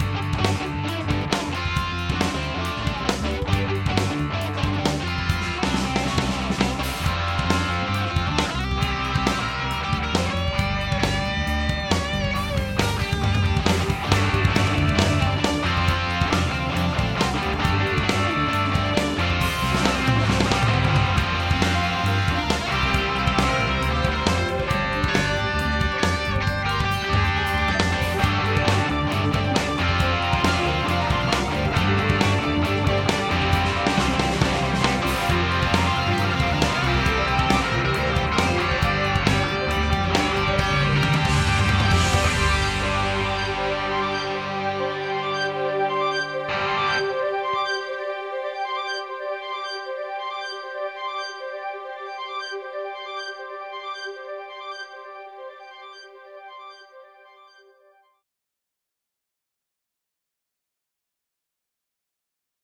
Studio test 2 - different drums - August 2011